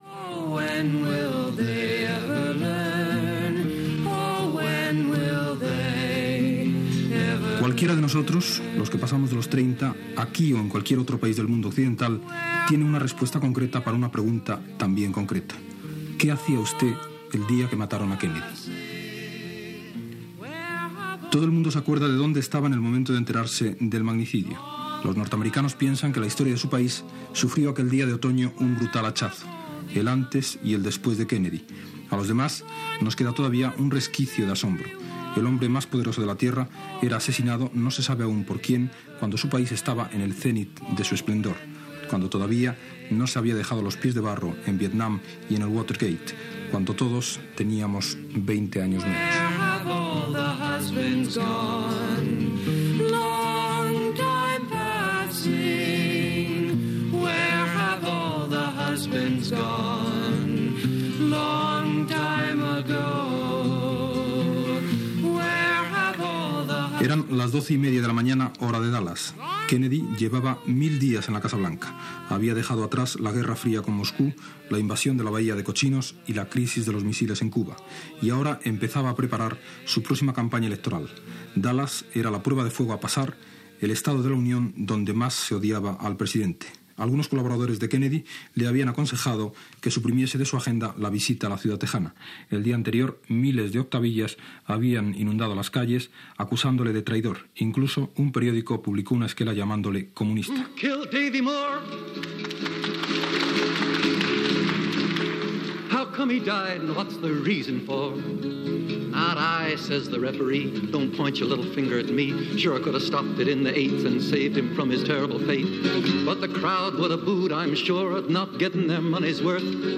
Programa especial
Gènere radiofònic Informatiu